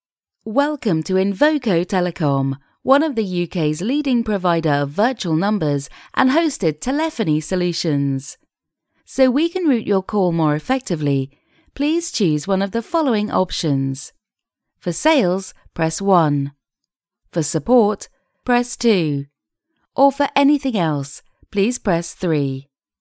IVR Voiceover – Female F2
Female voiceover – F2 – Up to 120 words professionally recorded.
Professionally recorded voiceover for IVR’s, welcome greetings, system voicemails, etc., for up to 120 words.